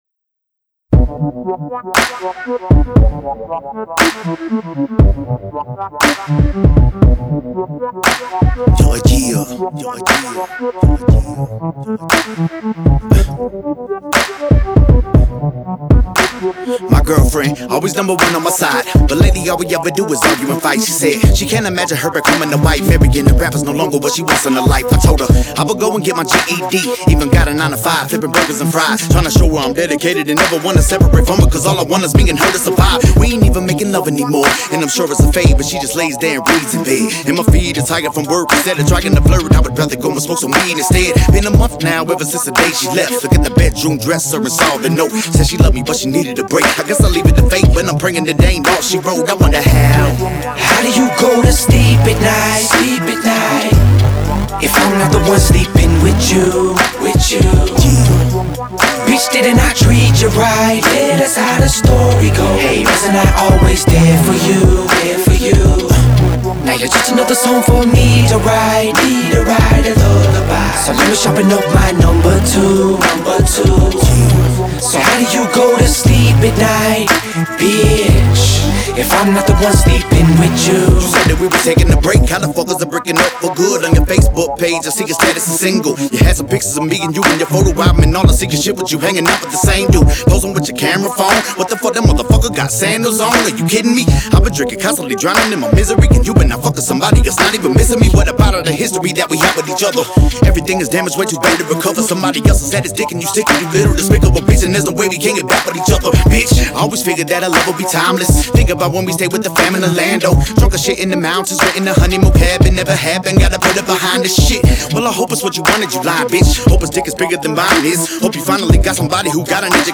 Genre: Hip Hop.